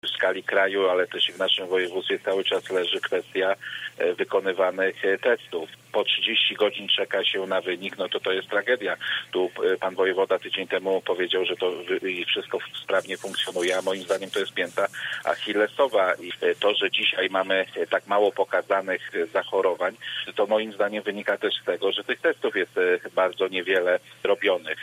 Konsekwencje społeczne, gospodarcze i polityczne wprowadzenia w Polsce stanu epidemii – tematem rozmowy polityków w Lubuskim Forum Radia Zachód.